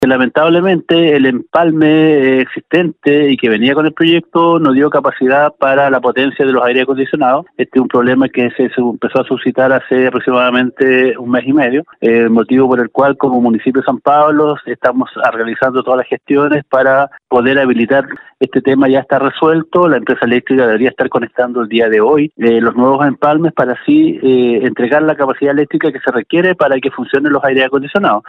Desde el municipio en tanto, el alcalde Juan Carlos Soto explicó que la calefacción de la escuela Julio Mohr funciona con electricidad.